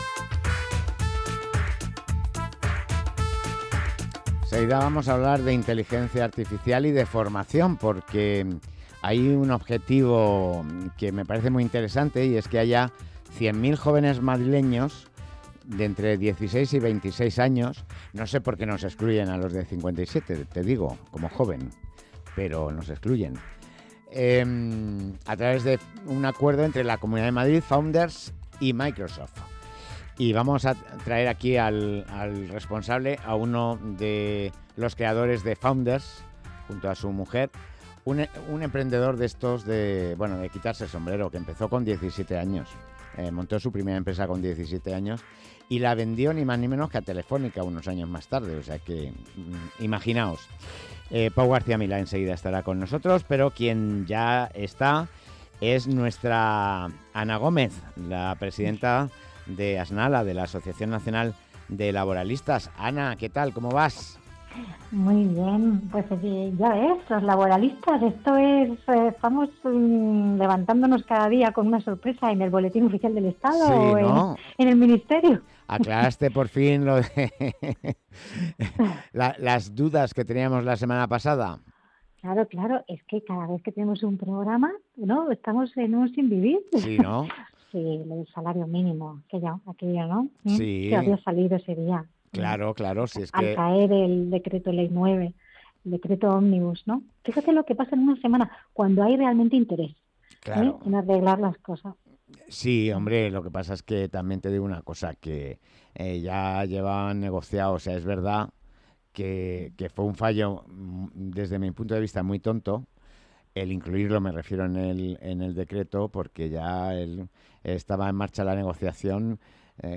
Entrevista
Hemos recibido en nuestros estudios de Ciudad de la Imagen